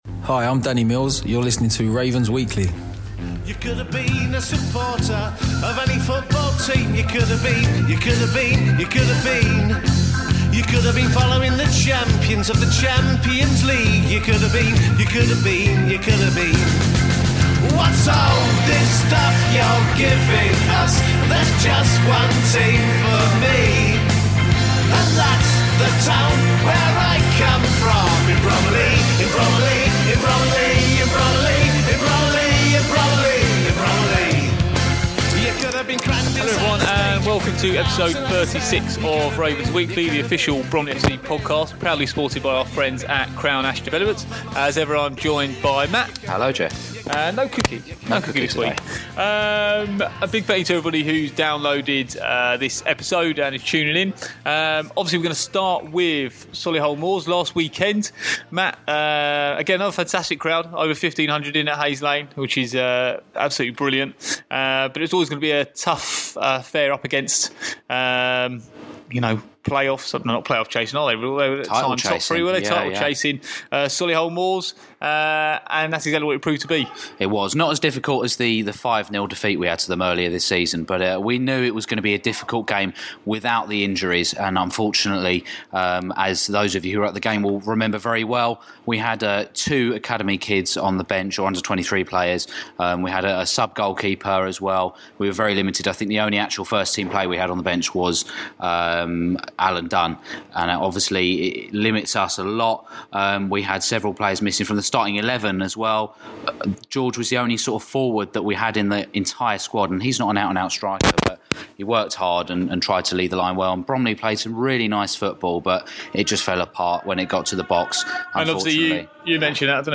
This week’s episode includes interviews